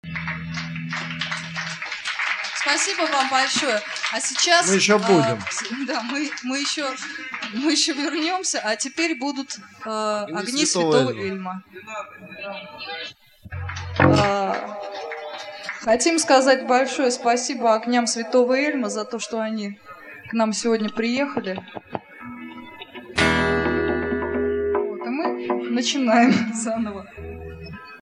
Концерт 14 февраля